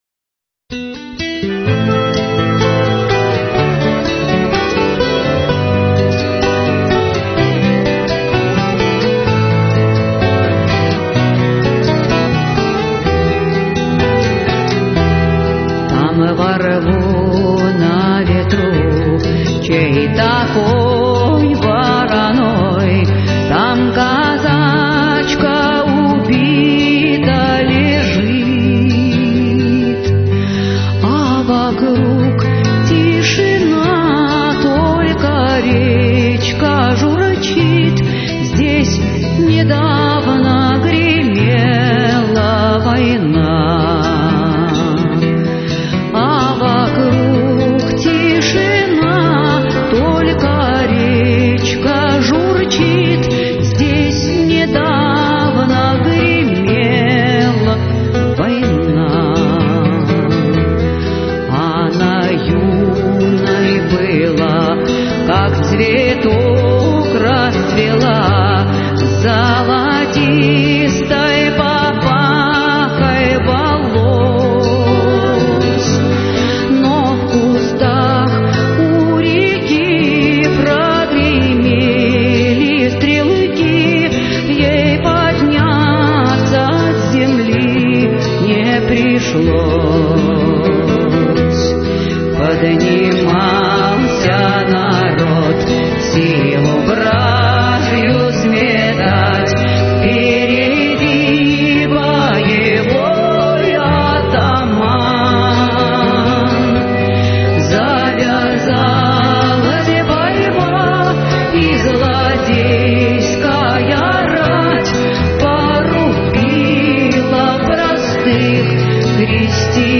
Авторская песня